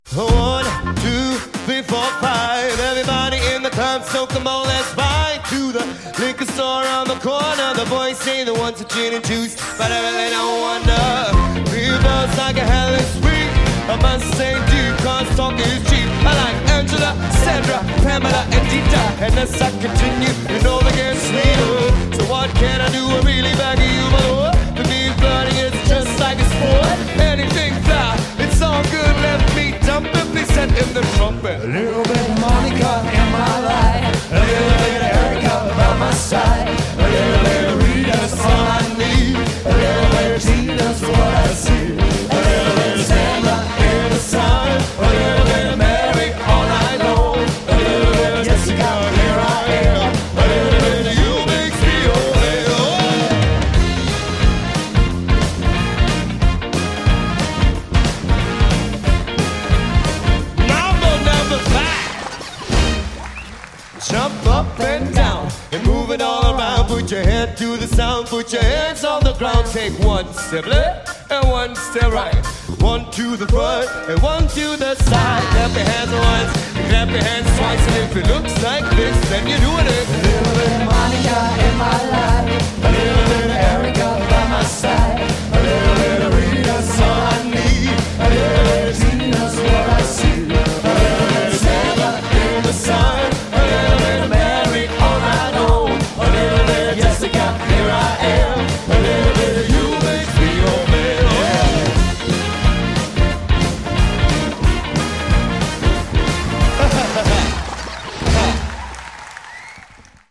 Sänger